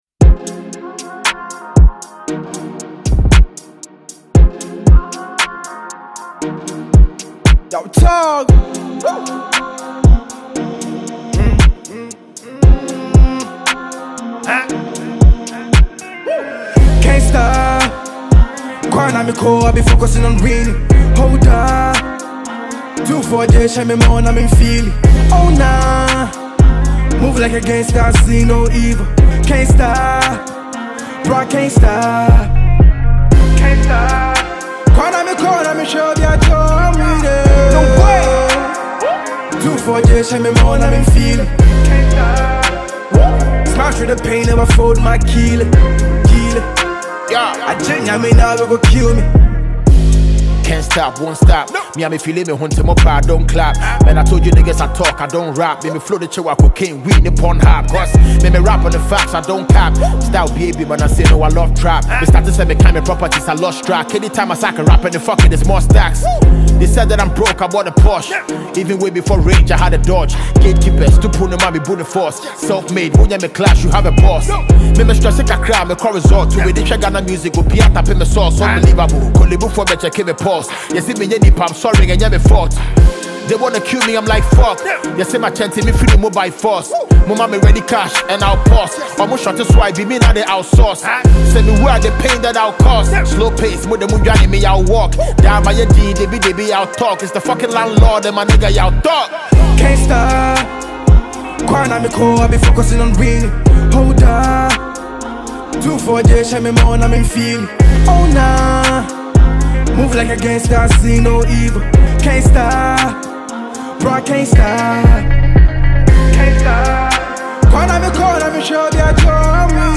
Verstile Ghanaian rapper